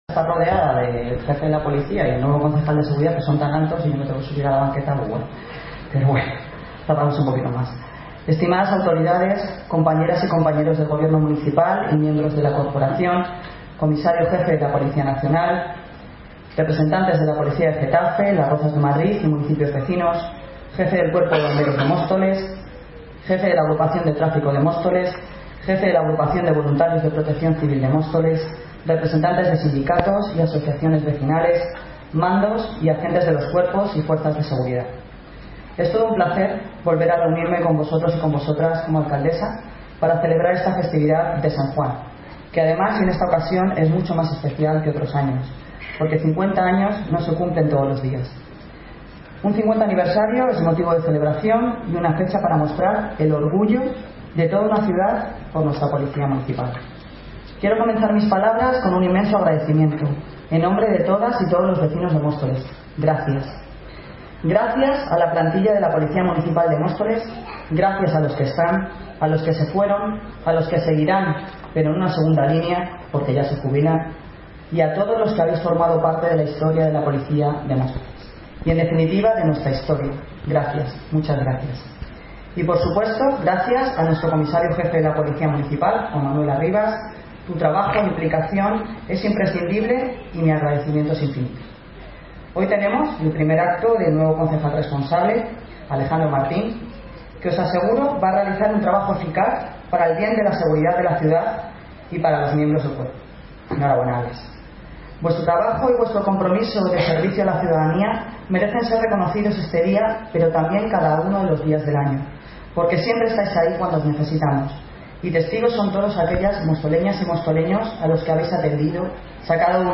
Noticia Fecha de publicación: 24 de junio de 2019 Durante el acto institucional, la alcaldesa Noelia Posse recordó que hoy, 50 años después de su creaci...
Audio - Noelia Posse (Alcaldesa de Móstoles) Sobre Patrón Policía Municipal